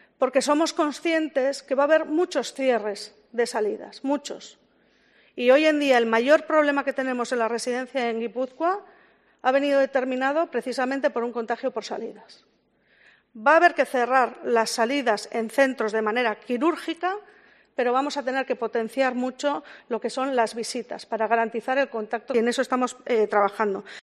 Maite Peña, diputada de Política Social de Gipuzkoa.
La diputada de Política Social de Gipuzkoa, Maite Peña, ha reivindicado este miércoles, durante el pleno de las Juntas Generales del territorio, que las medidas implantadas en las residencias en la segunda ola del Covid-19 están dando resultado como demuestra que durante los dos meses y 19 días de la primera ola se registraron 612 positivos en los centros de mayores frente a los 77 casos contabilizados durante el último mes y medio.